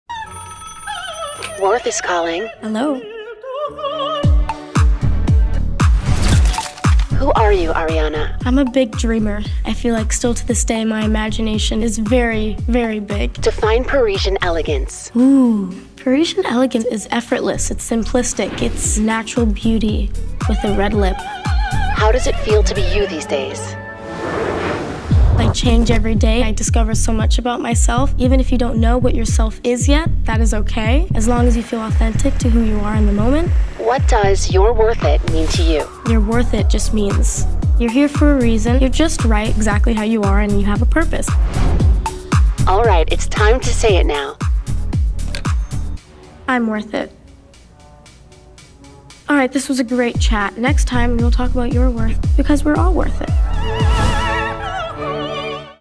Female
Approachable, Assured, Confident, Conversational, Corporate, Energetic, Engaging, Natural
Microphone: Rode Nt1-A